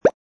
jump.mp3